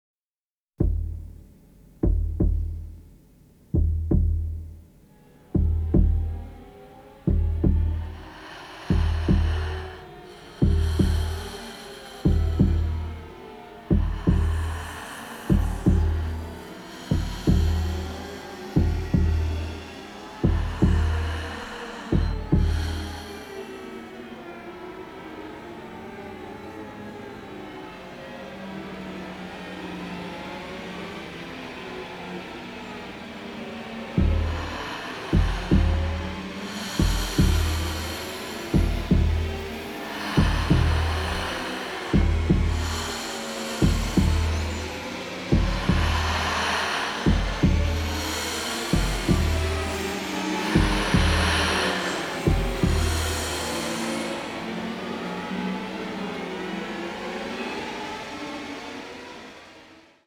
giallo score
sophisticated avant-garde sound
haunting tune